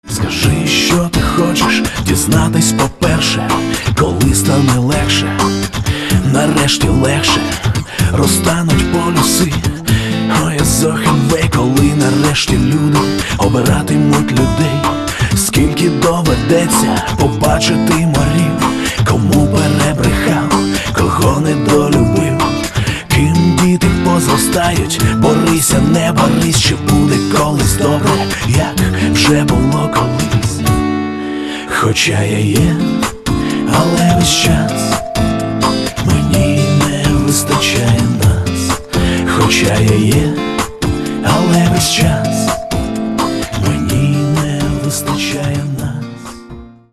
Каталог -> Хип-хоп